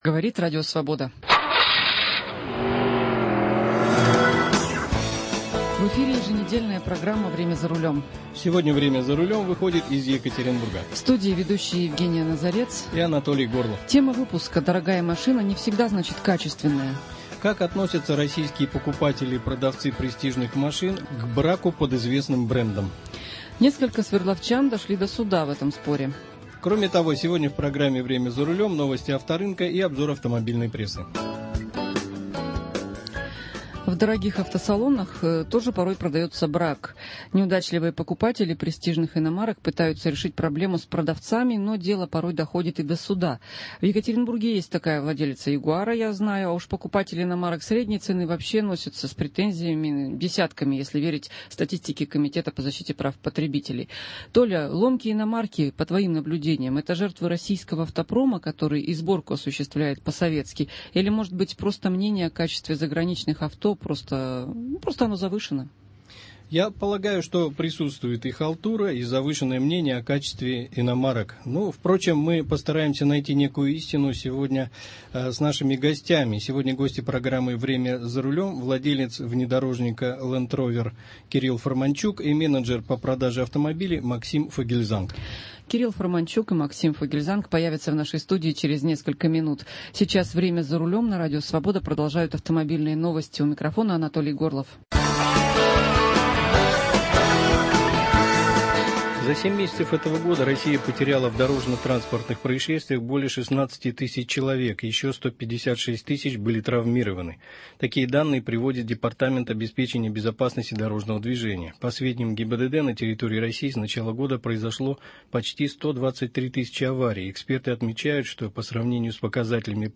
В дорогих автосалонах тоже продается брак. Неудачливые покупатели престижных иномарок пытаются решить проблему с продавцами, но дело доходит до суда. В студии программы «Время за рулем»